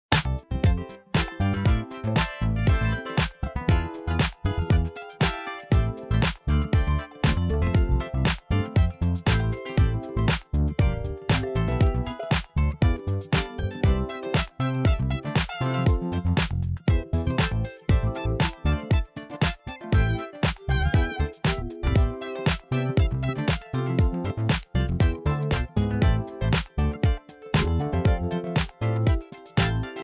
Introdução às cordas